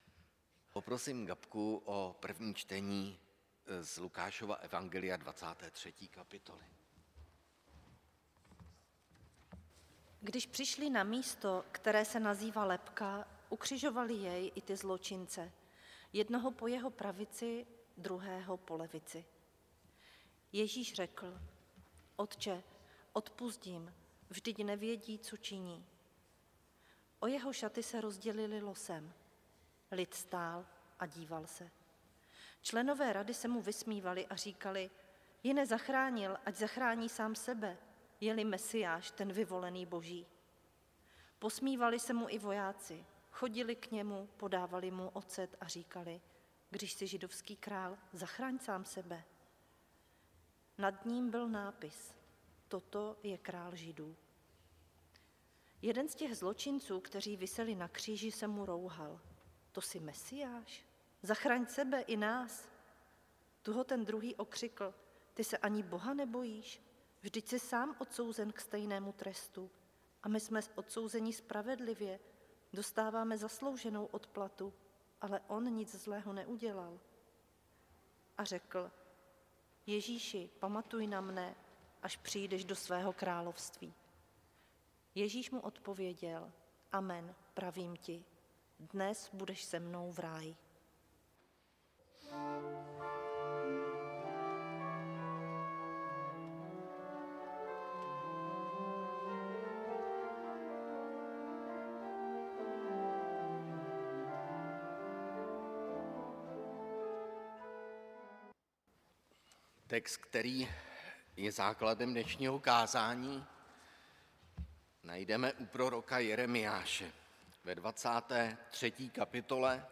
záznam kázání Jeremiáš 23, 1 – 6